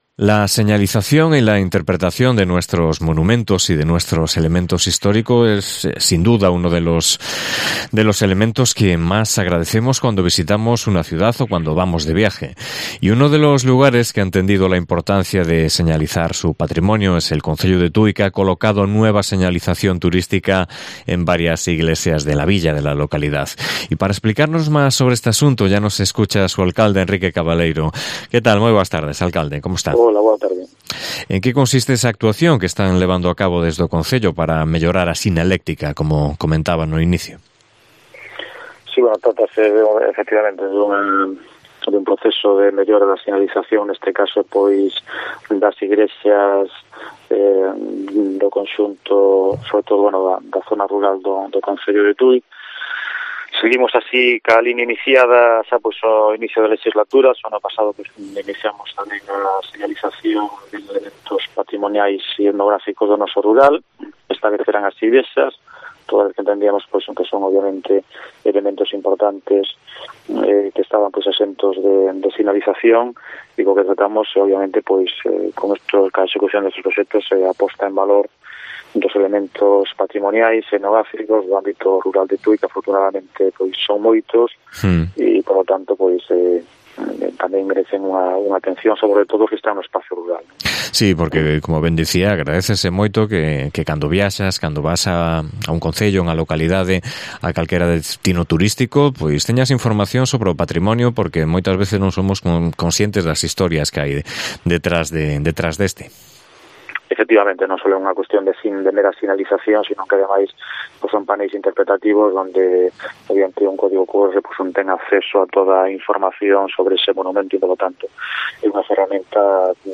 Con su alcalde, Enrique Cabaleiro, hablamos sobre estas jornadas y sobre los nuevos carteles de información turística que han situado en distintas iglesias de la localidad